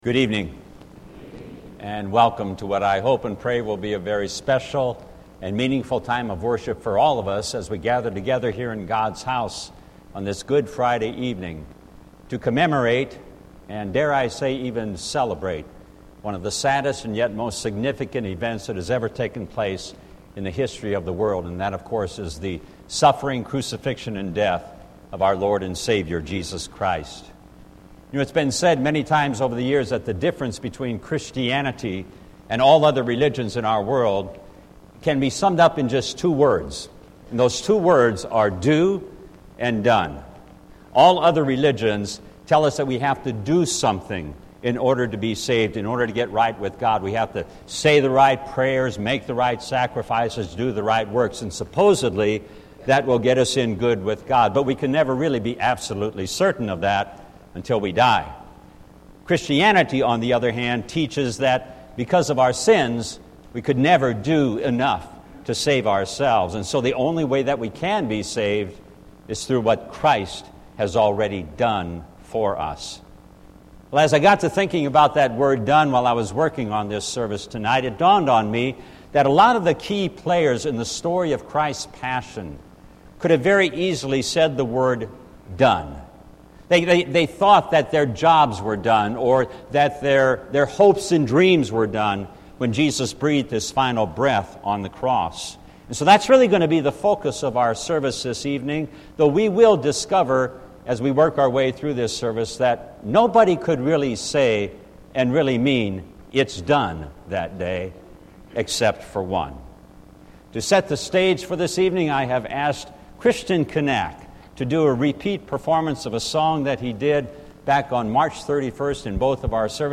A GOOD FRIDAY TENEBRAE SERVICE APRIL 19, 2019 INTRODUCTION: Good evening, and welcome to what I hope and pray will be a very special time of worship for you as we gather in God’s house on this Goo…
Good-Friday.mp3